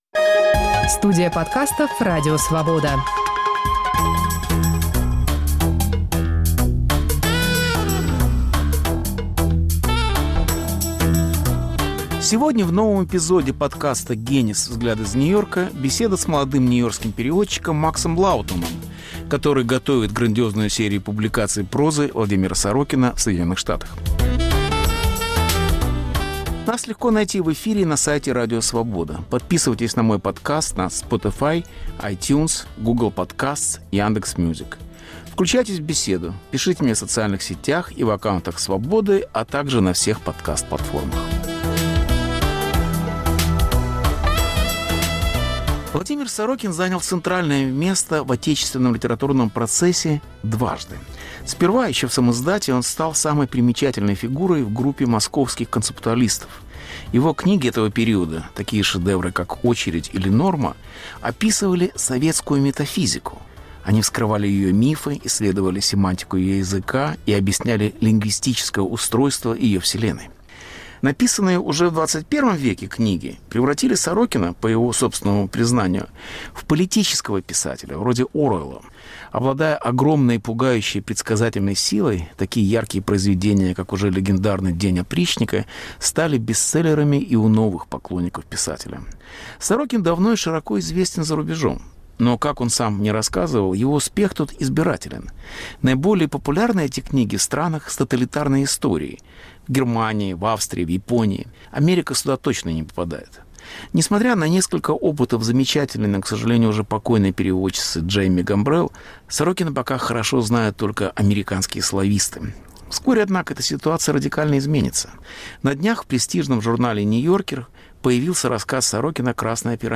Повтор эфира от 25 октября 2021 года.